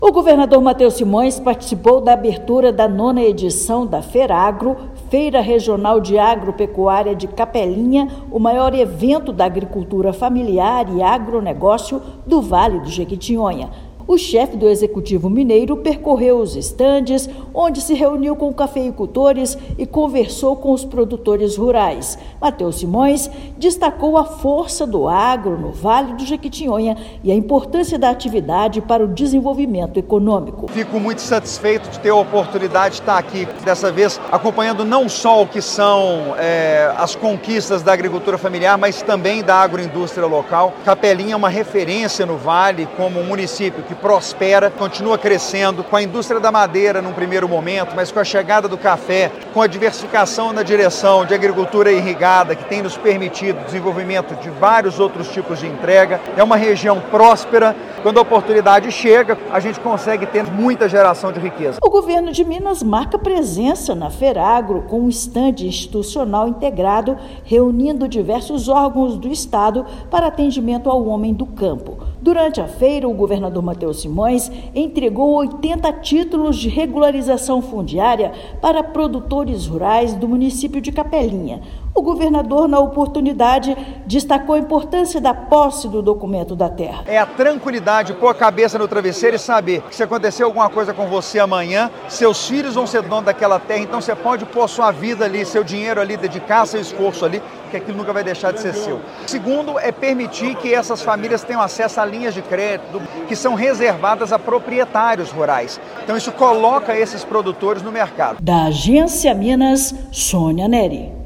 Participação do governador na maior feira de agricultura familiar e agronegócio do Vale do Jequitinhonha foi marcada pela entrega de títulos de propriedades rurais e apoio ao produtor. Ouça matéria de rádio.